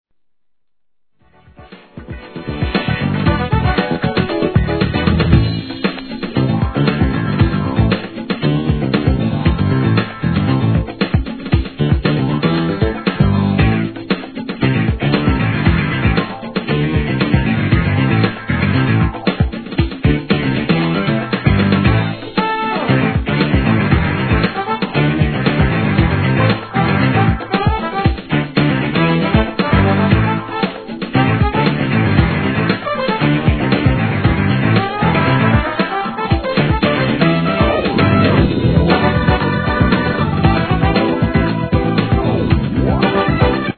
1. JAPANESE HIP HOP/R&B